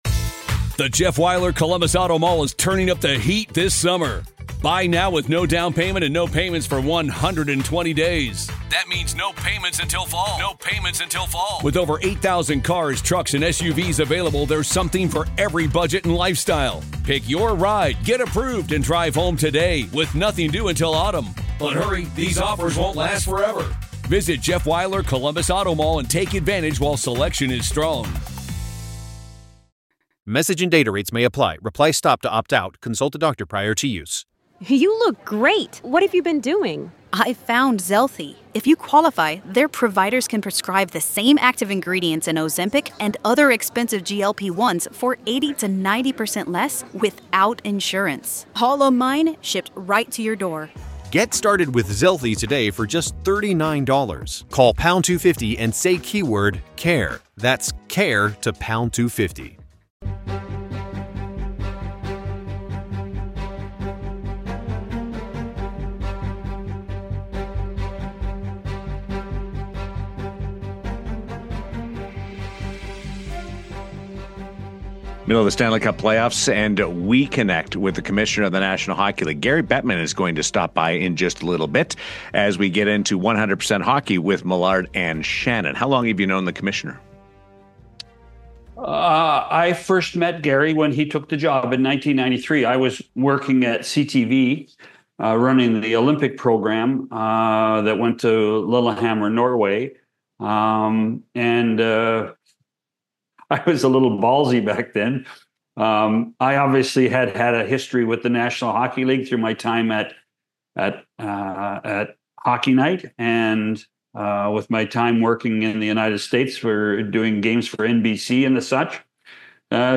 In this exclusive conversation, NHL Commissioner Gary Bettman joins 100% Hockey to tackle the big questions on every fan’s mind.